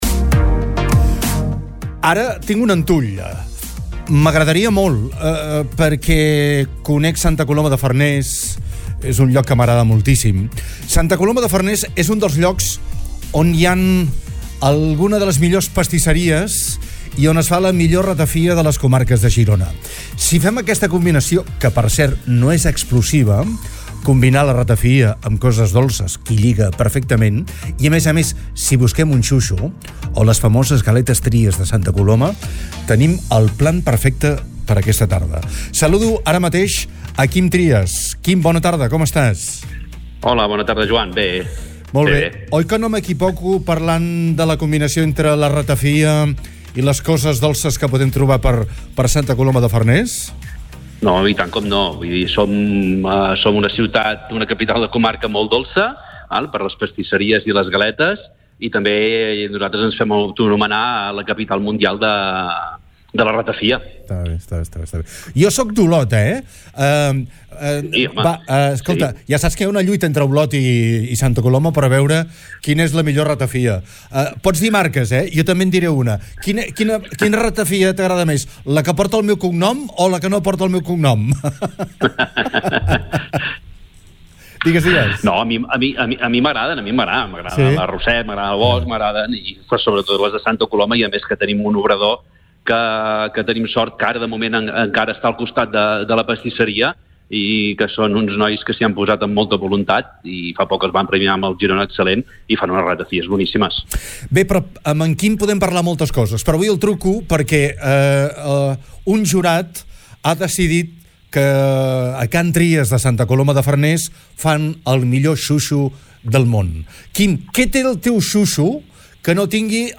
De fet, la pasta d’un xuixo és la del croissant, només que en les fases finals d’elaboració hi ha diferències: s’hi afegeix crema pastissera, s’enrotlla com si fos un caneló i es fregeix.